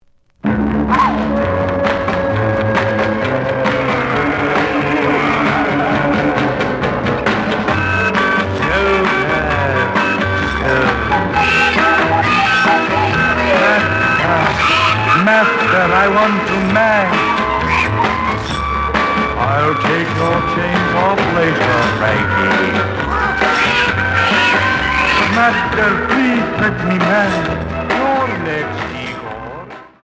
試聴は実際のレコードから録音しています。
●Genre: Rock / Pop
●Record Grading: VG~VG+ (両面のラベルに若干のダメージ。盤に歪み。プレイOK。)